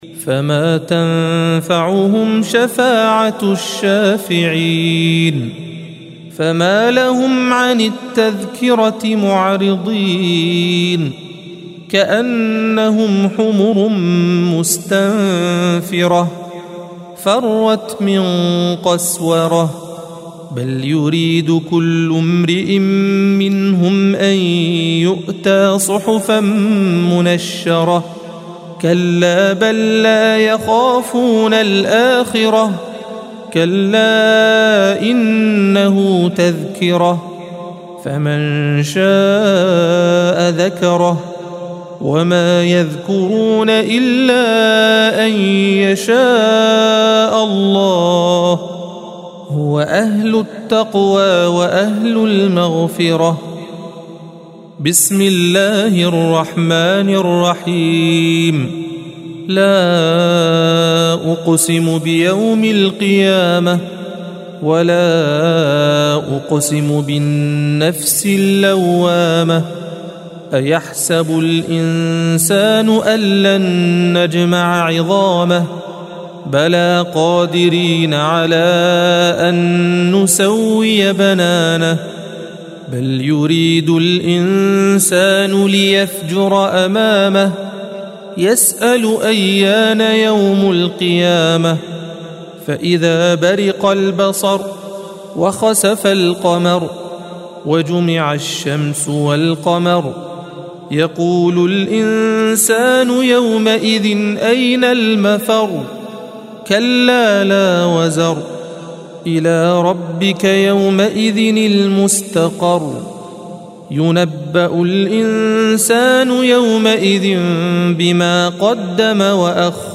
الصفحة 577 - القارئ